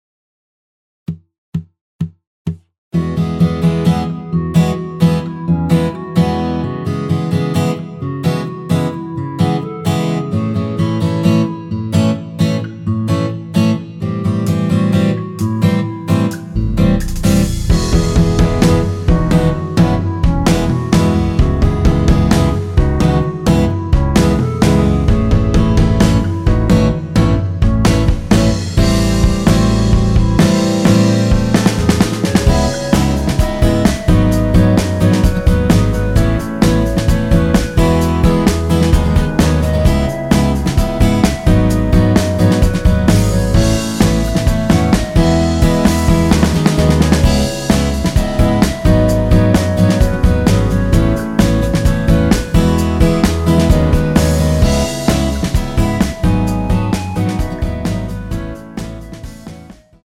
전주 없이 시작하는 곡이라 4박 카운트 넣어 놓았습니다.(미리듣기 확인)
원키에서(-1)내린 멜로디 포함된 MR입니다.
앞부분30초, 뒷부분30초씩 편집해서 올려 드리고 있습니다.